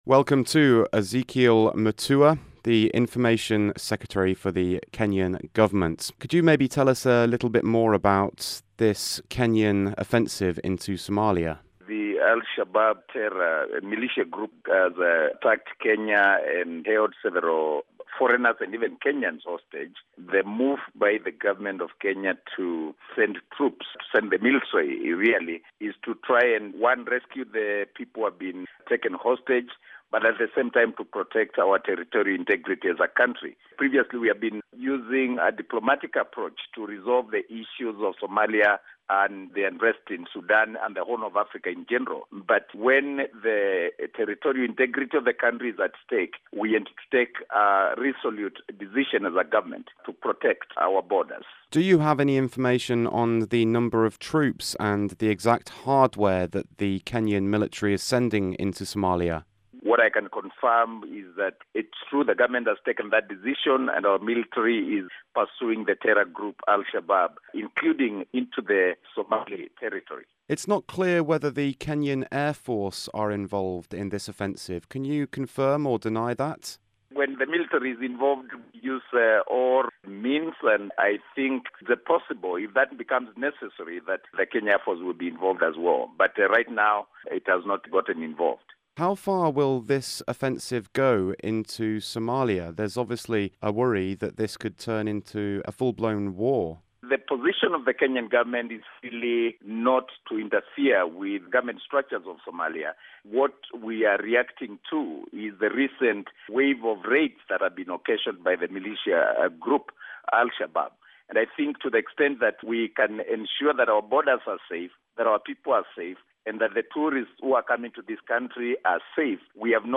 Interview: Ezekiel Mutua, Information Secretary, Kenyan government